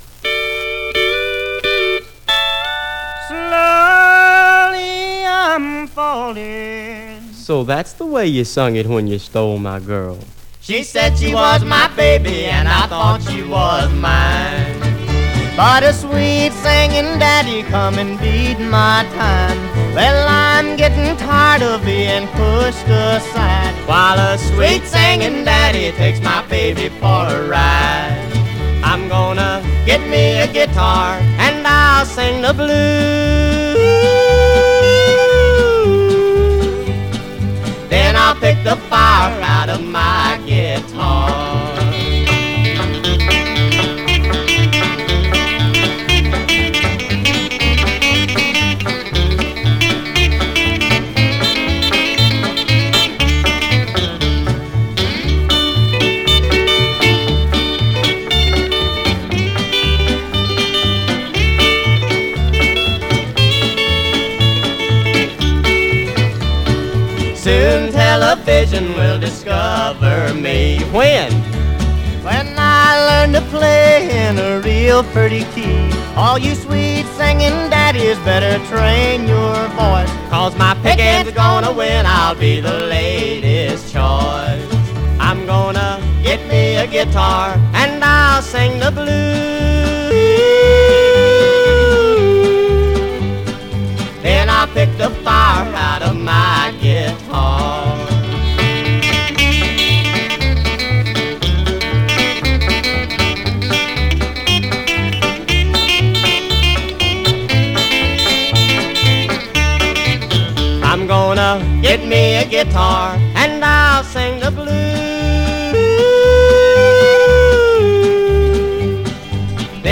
Rockabilly